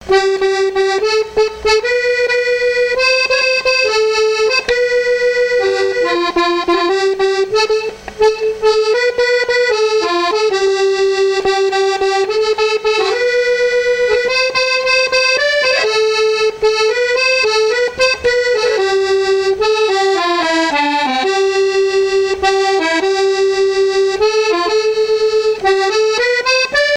danse : valse
Genre strophique
Pièce musicale inédite